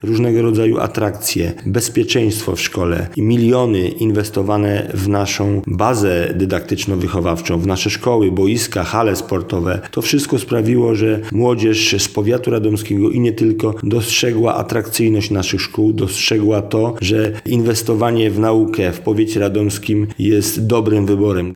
Zdaniem starosty radomskiego Waldemara Trelki, młodzież dostrzegła atrakcyjność powiatowych szkół średnich w wielu aspektach: